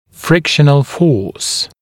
[‘frɪkʃ(ə)n(ə)l fɔːs][‘фрикш(э)н(э)л фо:с]сила трения